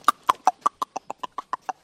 Звуки цоканья языком
Звук Быстро